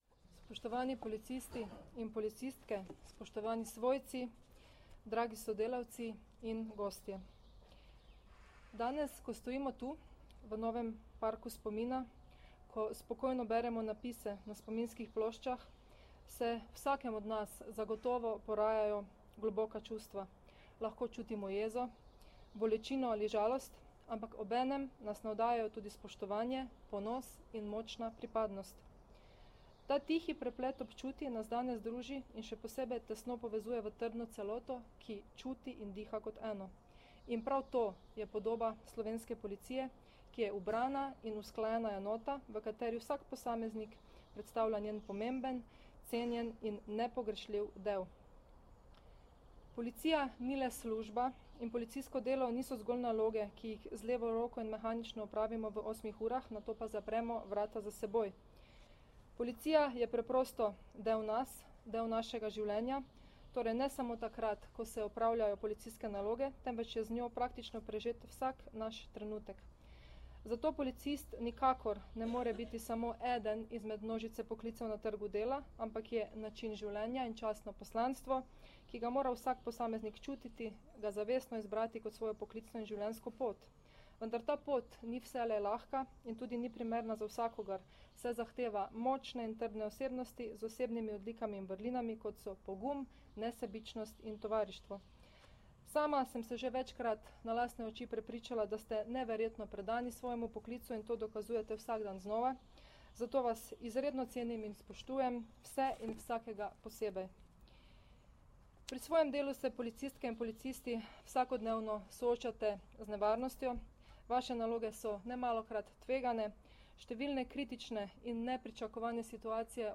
Zvočni posnetek govora notranje ministrice mag. Vesna Györkös Žnidar (mp3)